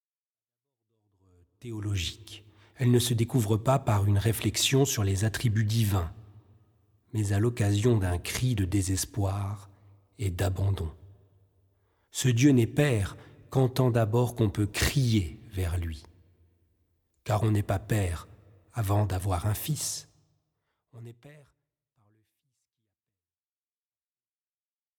méditations spirituelles